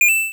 pickup.m4a